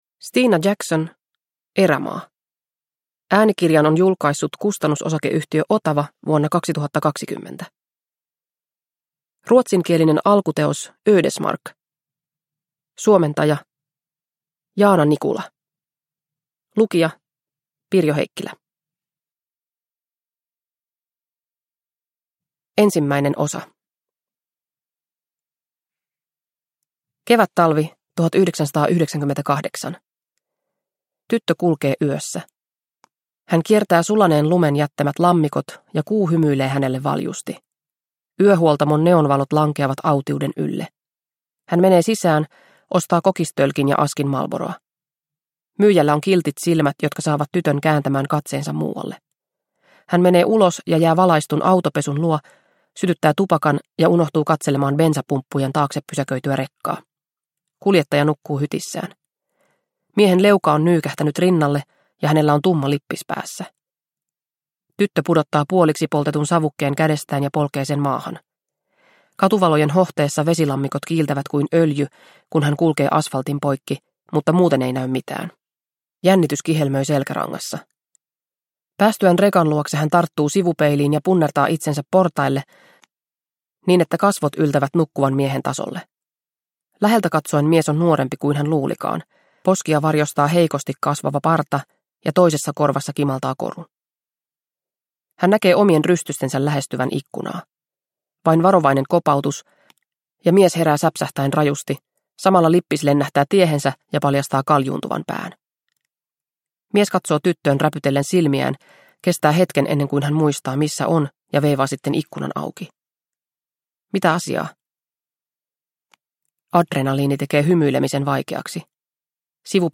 Erämaa – Ljudbok – Laddas ner